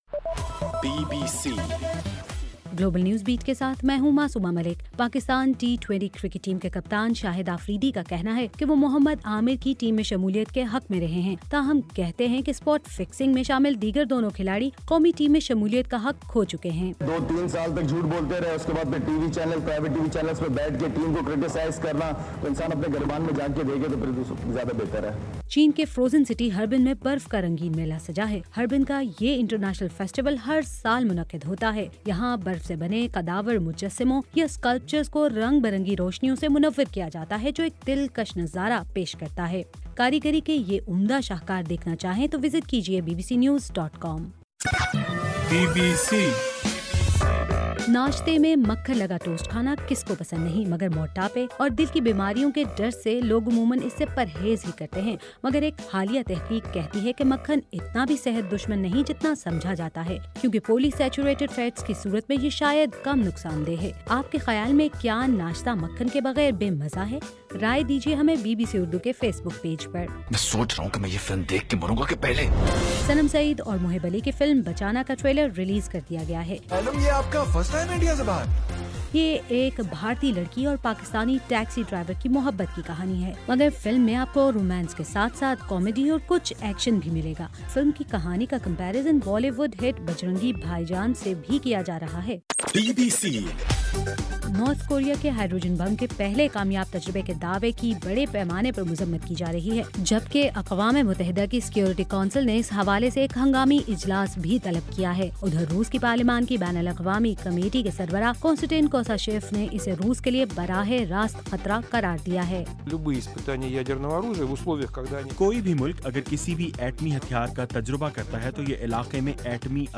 جنوری 06: رات 10 بجے کا گلوبل نیوز بیٹ بُلیٹن